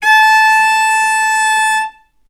vc-A5-ff.AIF